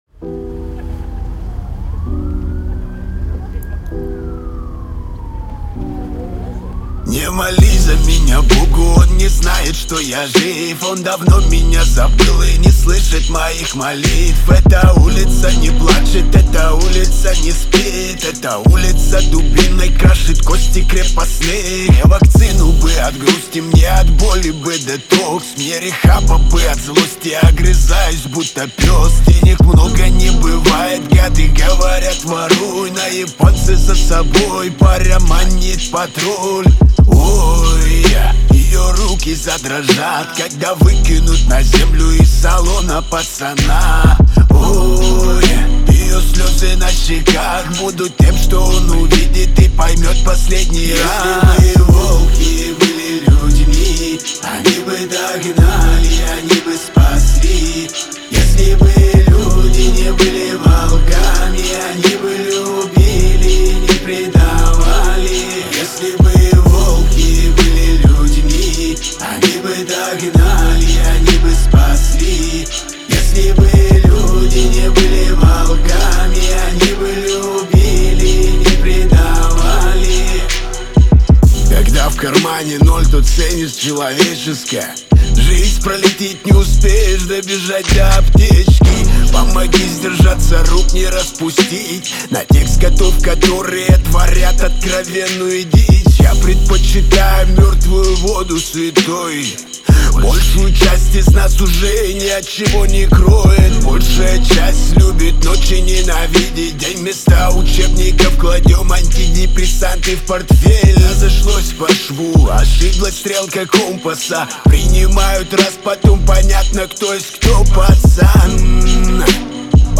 это мощный трек в жанре хип-хоп
отличается яркими битами и запоминающимся ритмом